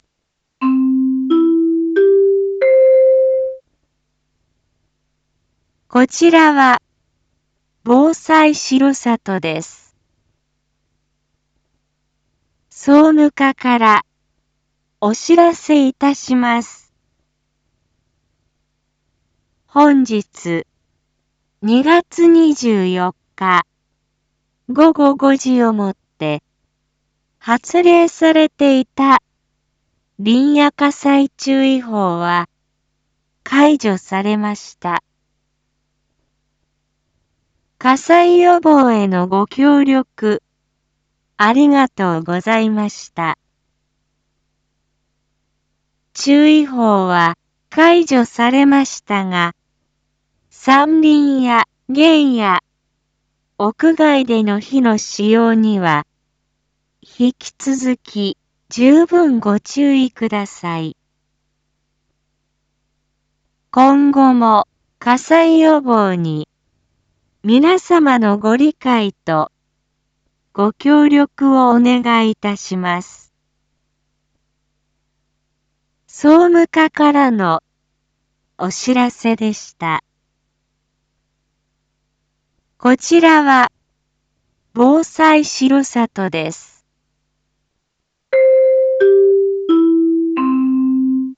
一般放送情報
Back Home 一般放送情報 音声放送 再生 一般放送情報 登録日時：2026-02-24 17:36:33 タイトル：林野火災注意報が解除されました インフォメーション：■ 解除日時 令和8年2月24日 午後5時00分 発令されていた「林野火災注意報」は解除されました。